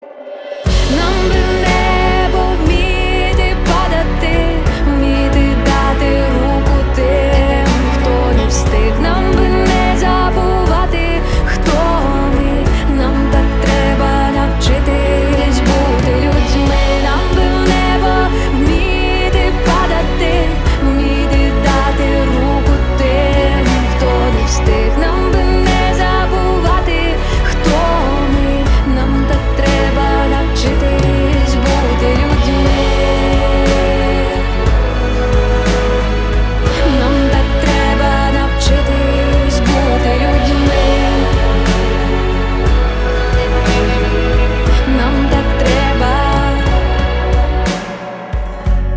• Качество: 320, Stereo
женский вокал
спокойные
инструментальные
indie pop
alternative